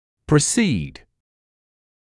[prɪ’siːd][при’сиːд]предшествовать (чему-либо)